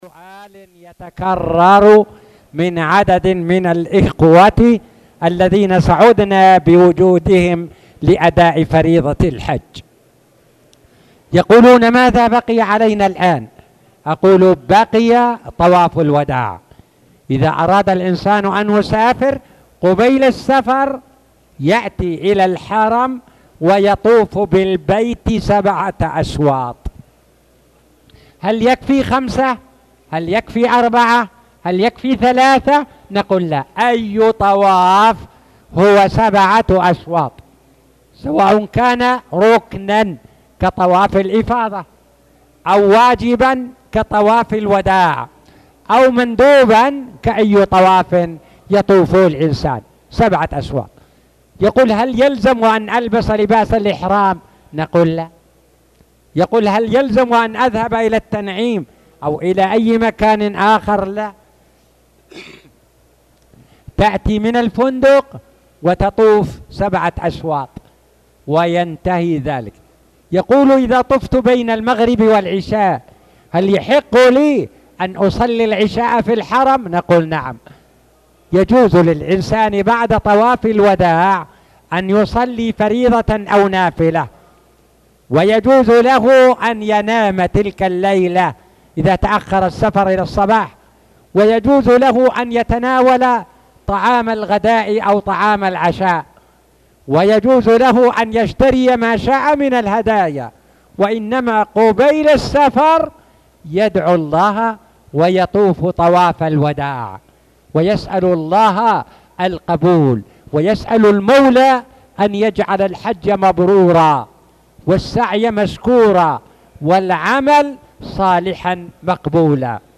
تاريخ النشر ٢٣ ذو الحجة ١٤٣٧ هـ المكان: المسجد الحرام الشيخ